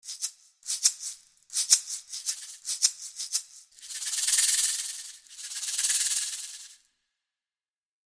Latin American Rumba Shaker (Maraca)
Drumwavy-51-Latin-American-Rumba-Shaker-Maraca.mp3